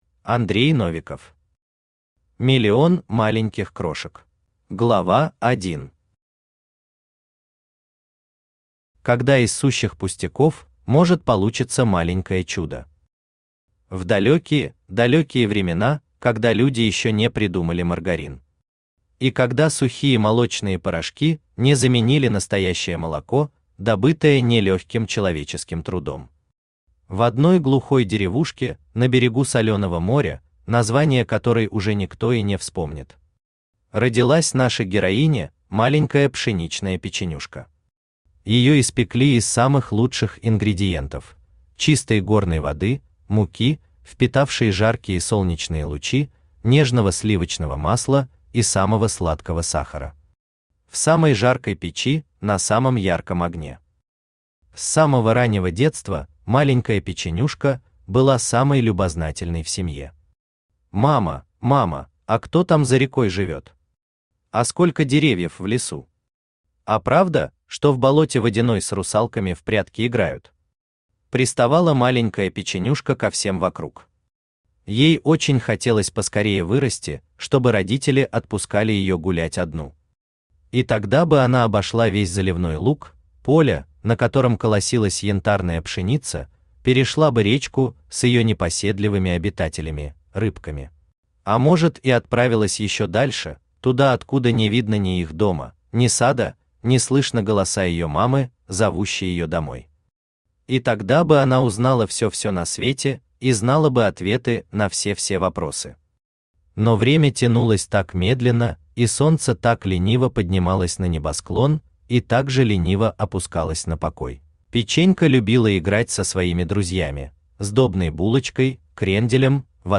Аудиокнига Миллион маленьких крошек | Библиотека аудиокниг
Aудиокнига Миллион маленьких крошек Автор Андрей Викторович Новиков Читает аудиокнигу Авточтец ЛитРес.